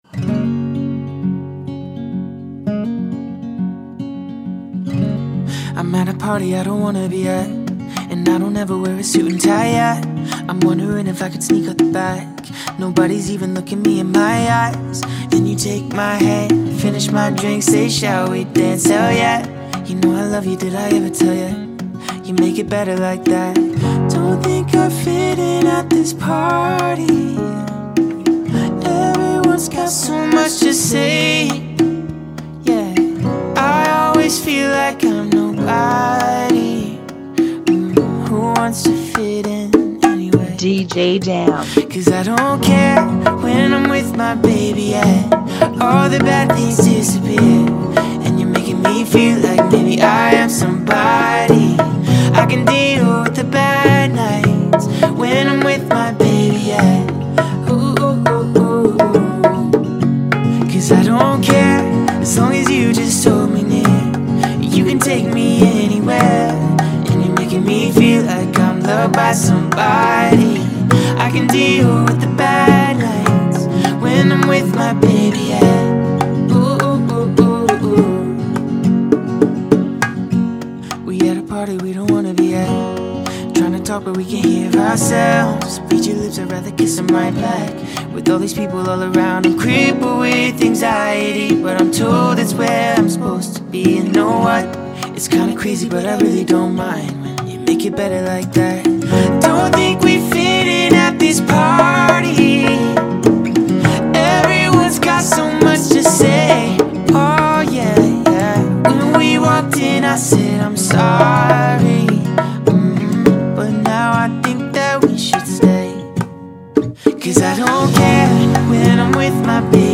102 BPM
Genre: Bachata Remix